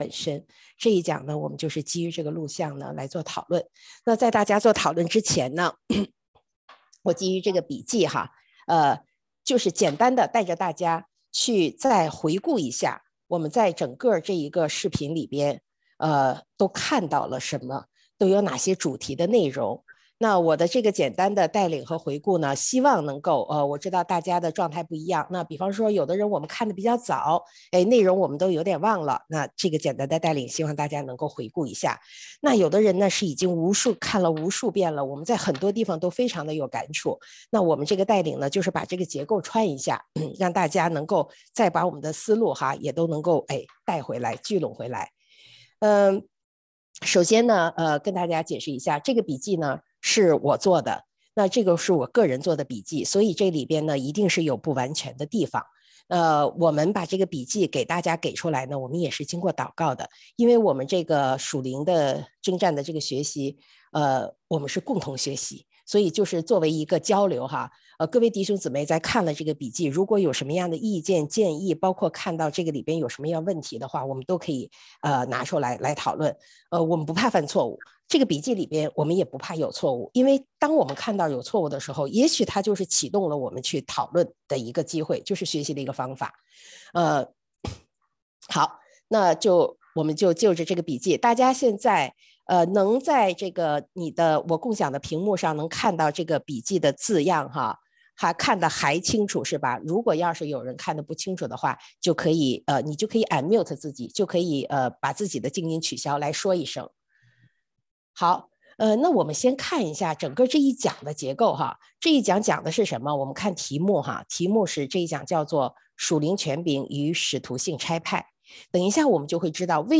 第1課答疑录音
1-何谓属灵权柄使徒性的差派答疑.mp3